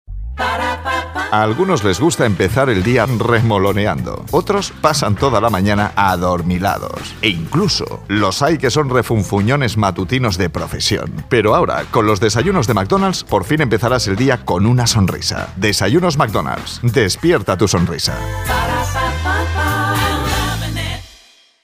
Mesa Soundcraft Altavoces Yamaha Microfono Sennheiser MD 441 U Software Adobe Audition
Sprechprobe: Werbung (Muttersprache):
My accent is spanish neutral She has a friendly, expressive, seductive and professional voice.